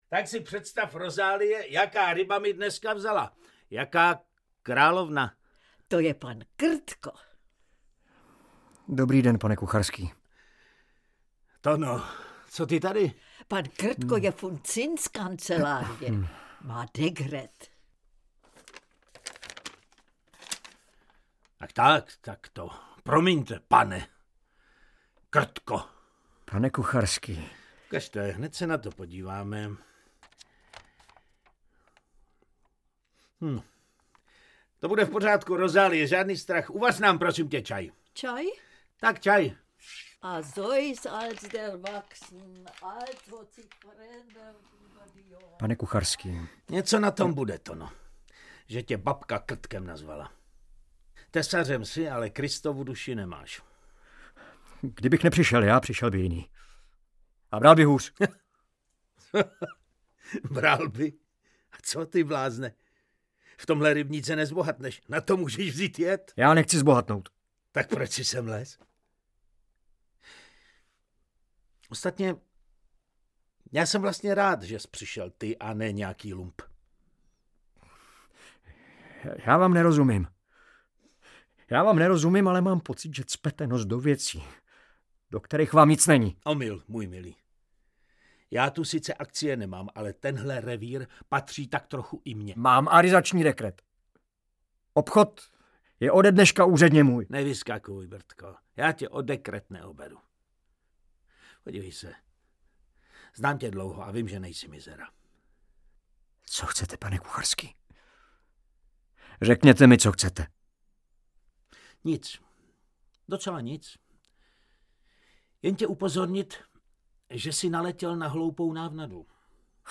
Celý popis Rok vydání 2019 Audio kniha Zkrácená verze Ukázka z knihy 199 Kč Koupit Ihned k poslechu – MP3 ke stažení Potřebujete pomoct s výběrem?